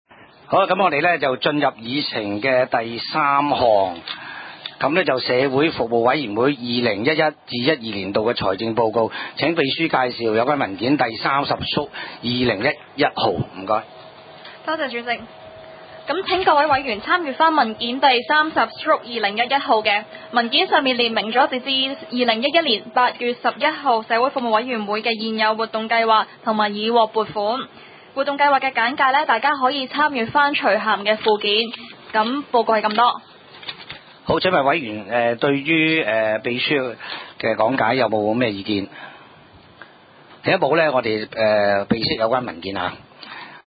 第三屆觀塘區議會屬下 社會服務委員會第二十五次會議記錄